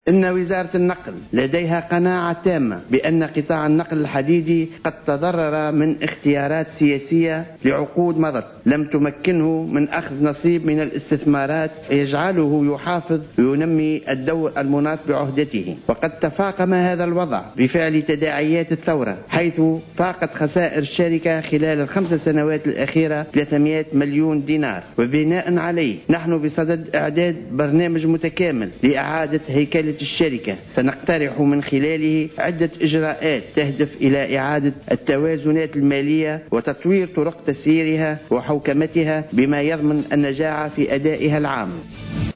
Le ministre du Transport, Anis Ghedira a déclaré dans une intervention sur les ondes d Jawhara FM aujourd’hui, samedi 21 mai 2016, que la Société nationale des chemins de fer (SNCFT), a enregistré ces cinq dernières années, de grandes pertes, qui s’élèvent à plus de 300 millions de dinars.